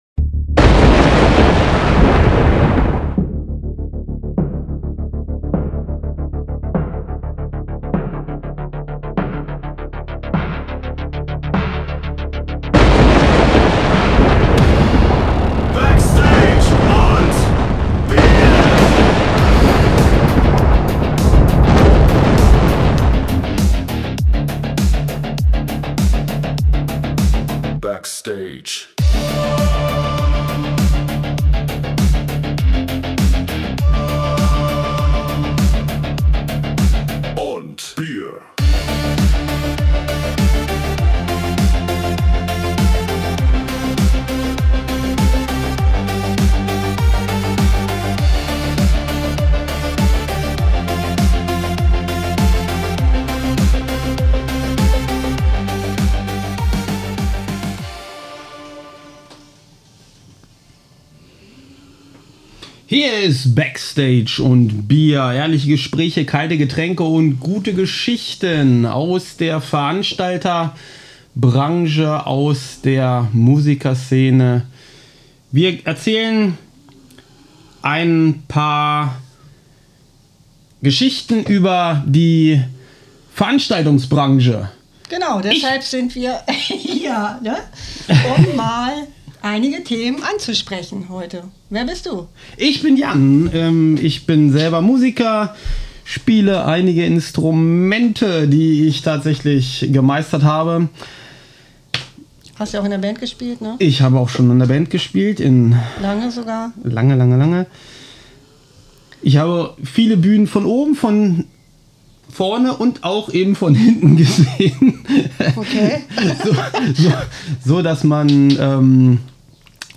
Natürlich läuft nicht alles glatt: Der Hund schnarcht ins Mikro, das Aufnahmegerät macht Zicken, und nebenbei reden wir uns den Frust über die aktuelle Lage der Live-Szene von der Seele. Was läuft gerade schief bei Konzerten, Festivals und Booking? Warum haben Newcomer kaum noch Bühnen?
Real Talk. Ohne Skript. Mit Schnarchhund.